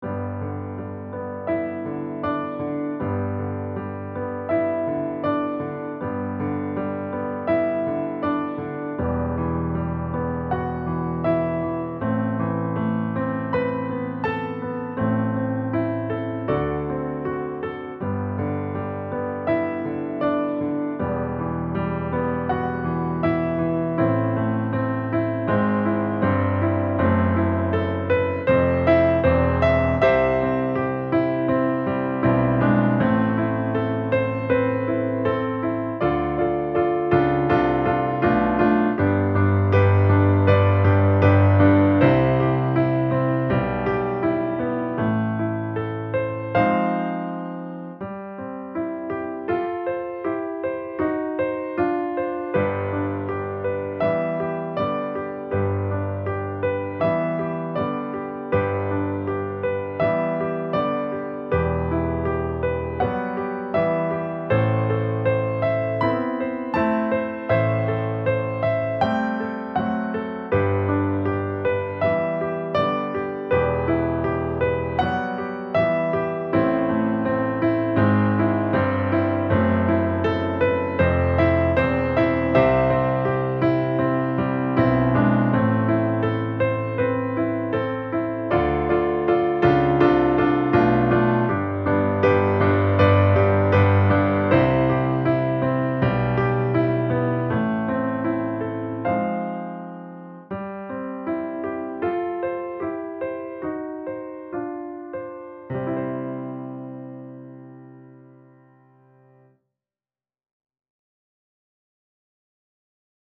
• piano accompaniment